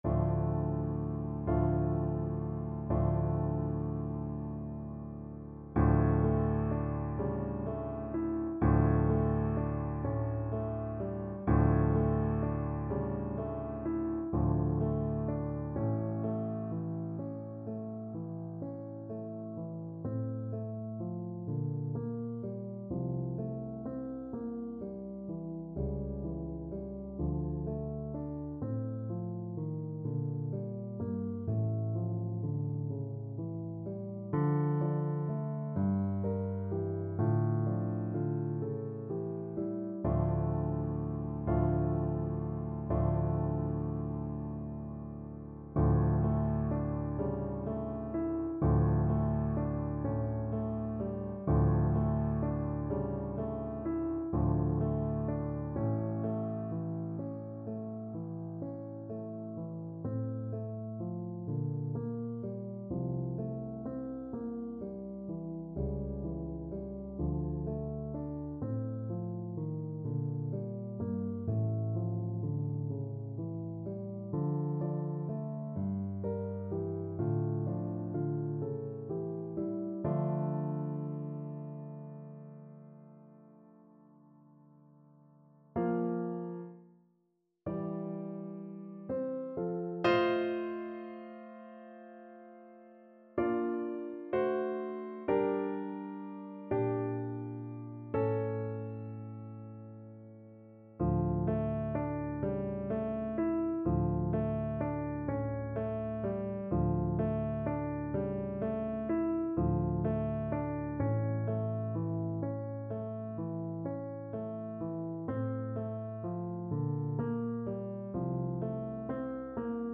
Play (or use space bar on your keyboard) Pause Music Playalong - Piano Accompaniment Playalong Band Accompaniment not yet available transpose reset tempo print settings full screen
F major (Sounding Pitch) (View more F major Music for Cello )
6/8 (View more 6/8 Music)
. = 42 Andante con moto (View more music marked Andante con moto)
Classical (View more Classical Cello Music)